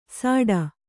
♪ sāḍa